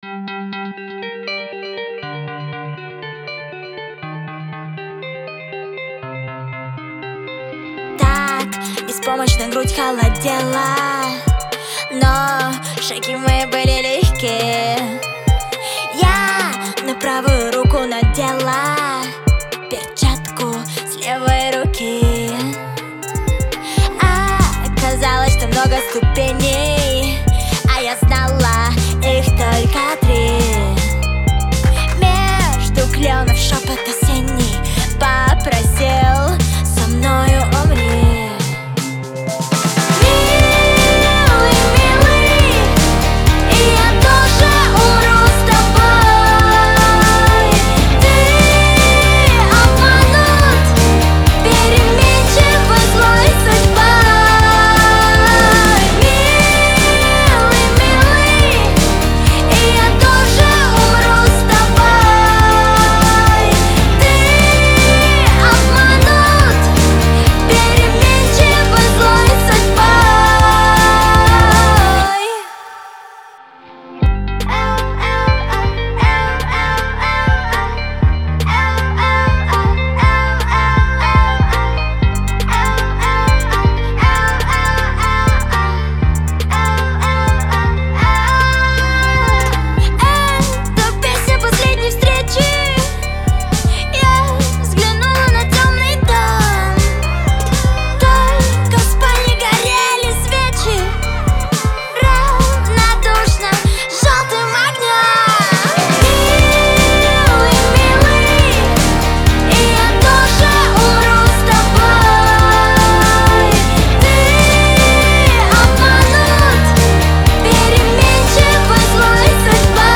это трогательный и меланхоличный трек в жанре поп-баллады.